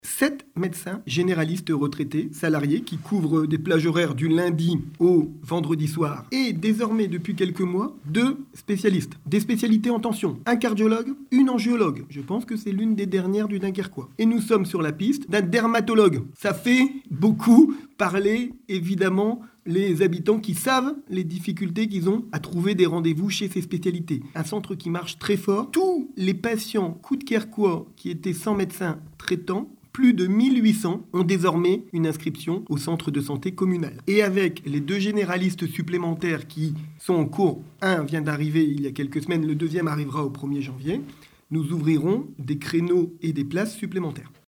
David Bailleul, le maire de Coudekerque-Branche.